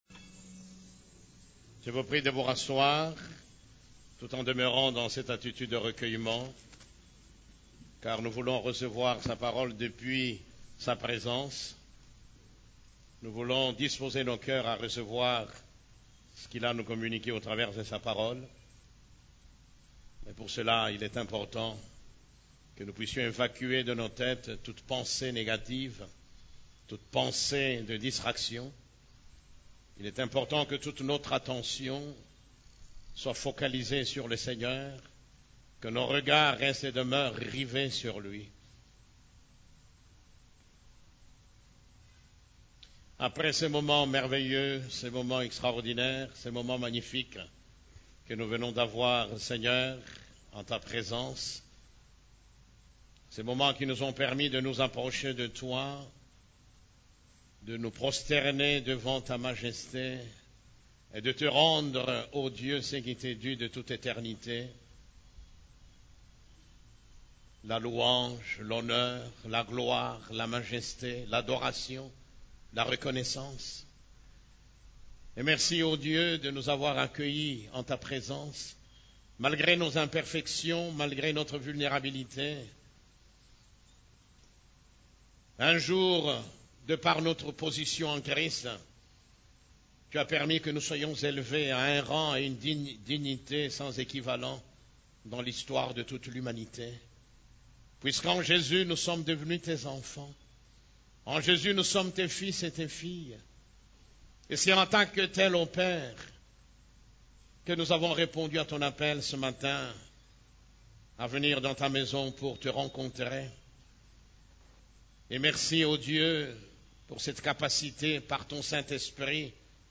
CEF la Borne, Culte du Dimanche, L'entrée du lieu secret 7